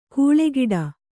♪ kūḷe giḍa